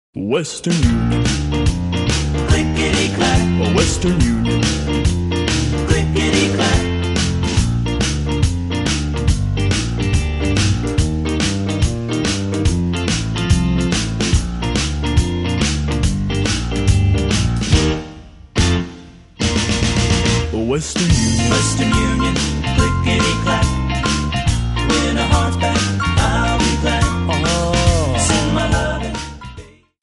Bb
MPEG 1 Layer 3 (Stereo)
Backing track Karaoke
Pop, Oldies, 1960s